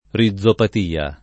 rizopatia [ ri zz opat & a ] s. f. (med.)